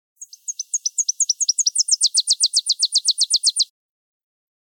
Song of the Tennessee Warbler